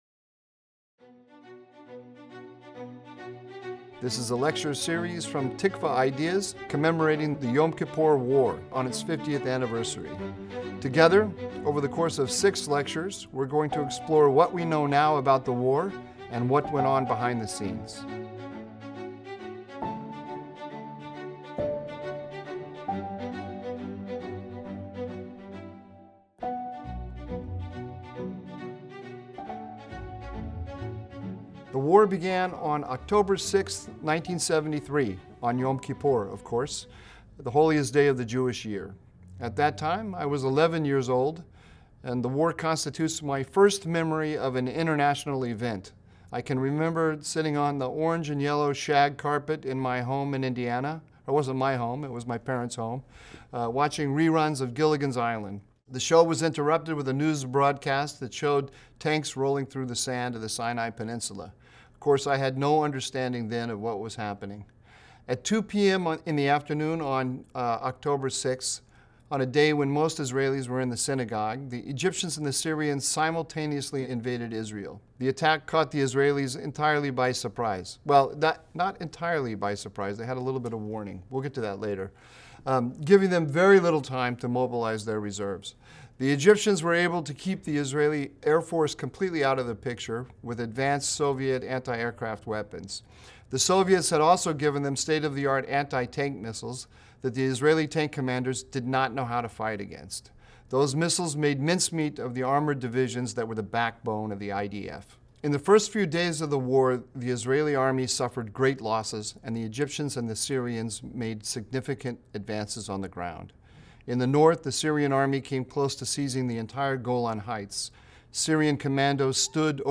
In this opening lecture